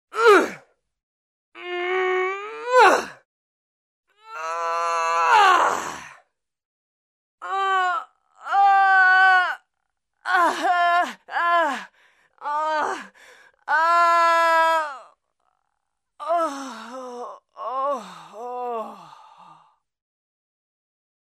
На этой странице собраны натуральные звуки человеческого тела: сердцебиение, дыхание и другие физиологические процессы.
Стонет, мучается женщина